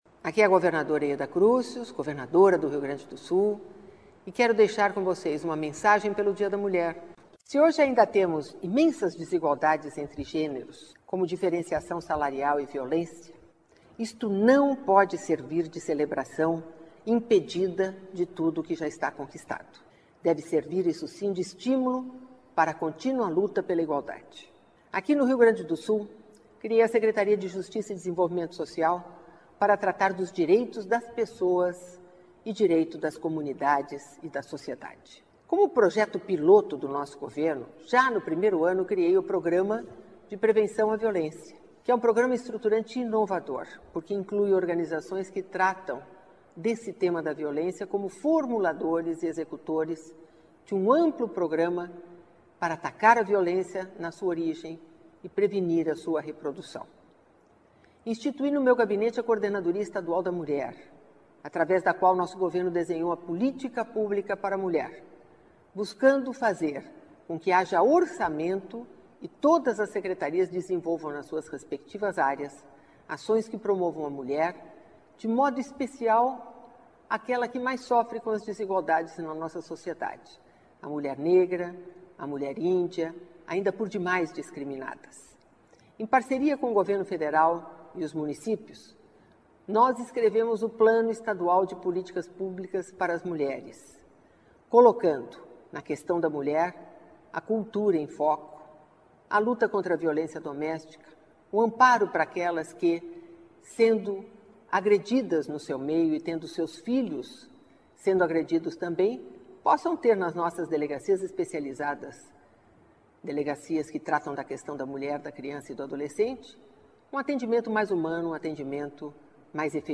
Mensagem da governadora Yeda Crusius em homenagem ao Dia Internacional da Mulher